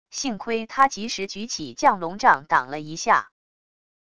幸亏他及时举起降龙杖挡了一下wav音频生成系统WAV Audio Player